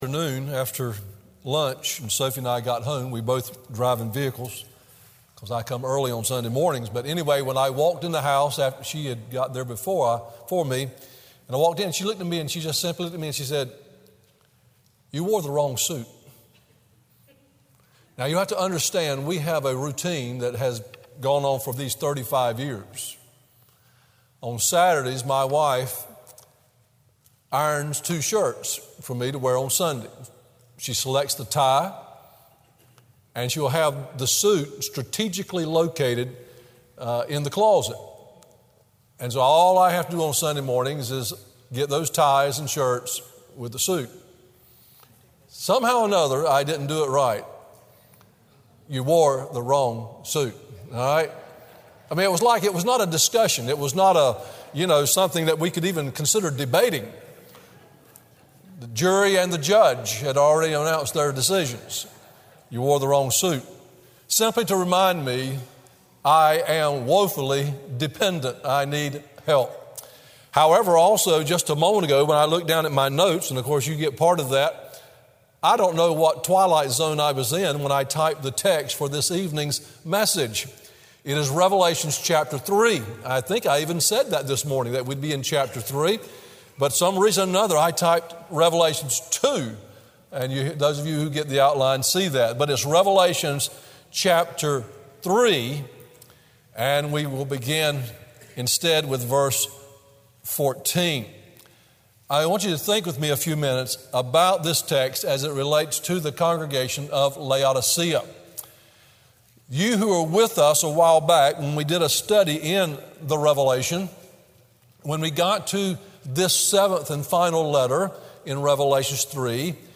Evening WorshipRevelation 3:14-22